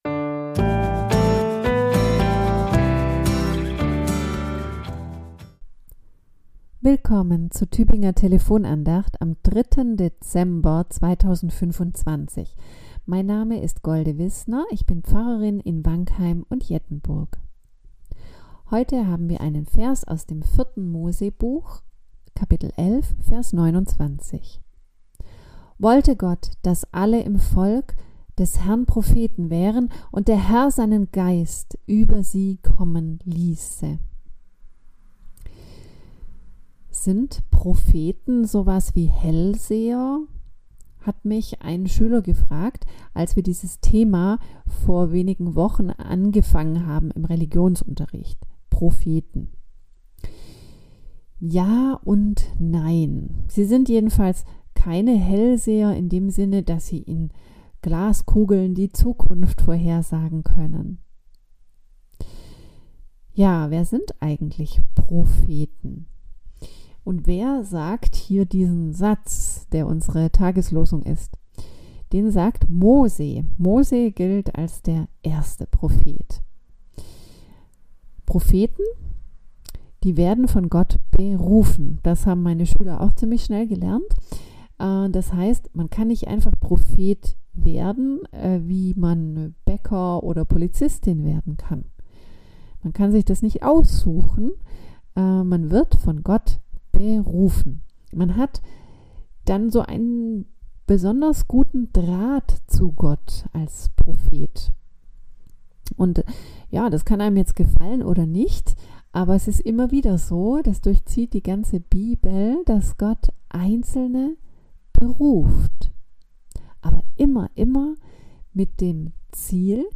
Andacht zum Ersten Advent